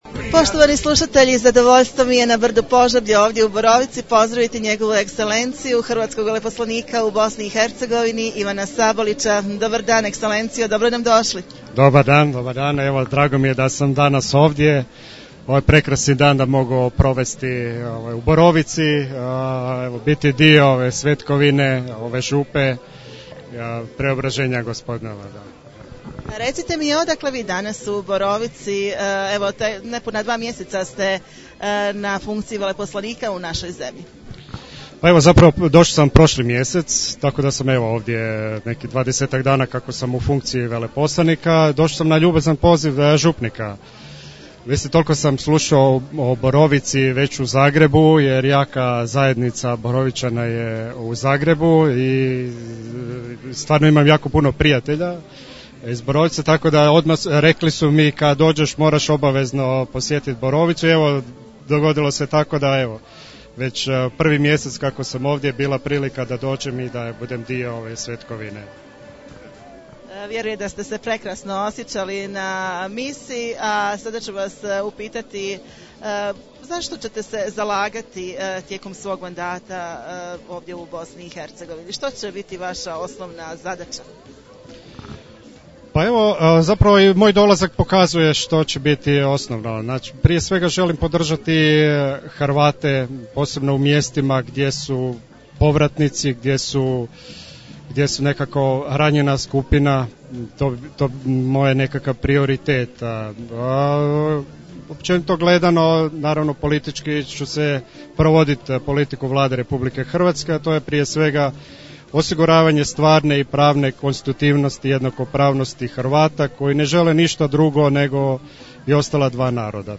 Razgovor s Hrvatskim veleposlanikom u Borovici